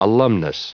Prononciation du mot alumnus en anglais (fichier audio)
Prononciation du mot : alumnus